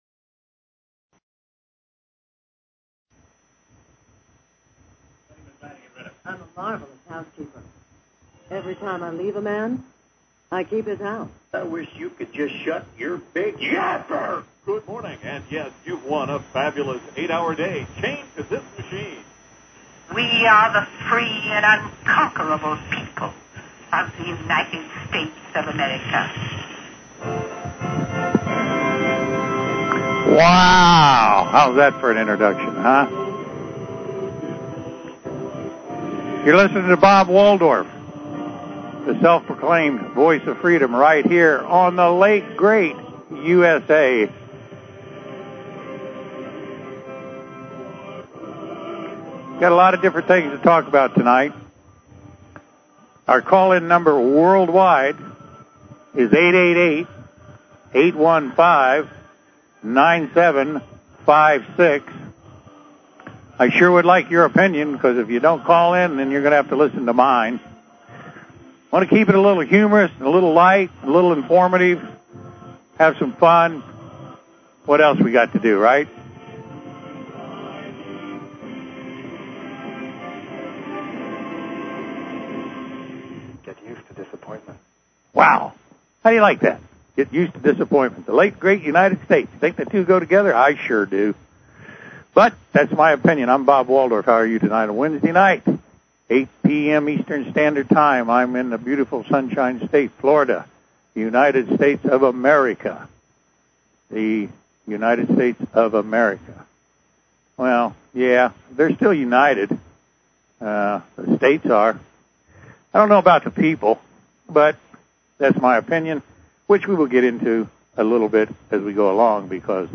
Talk Show Episode, Audio Podcast, The_Late_Great_USA and Courtesy of BBS Radio on , show guests , about , categorized as